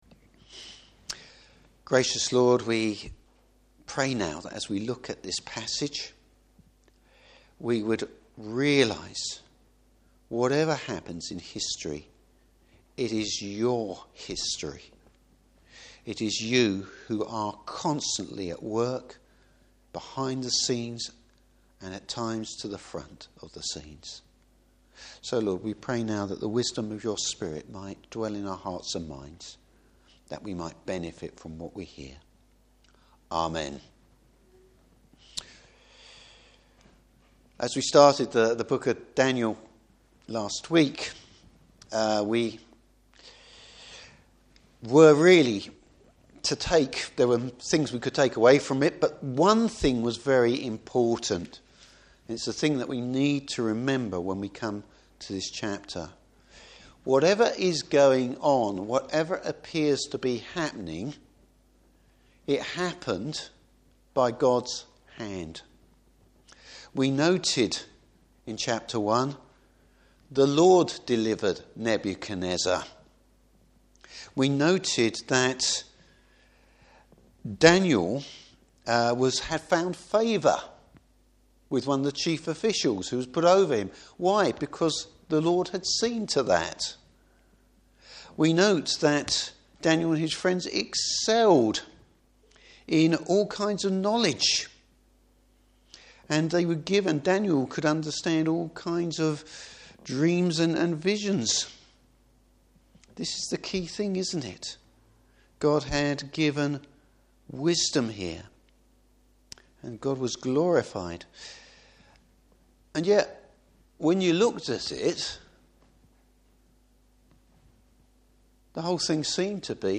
Service Type: Evening Service Who really rules over history?! Topics: The sovereignty of God.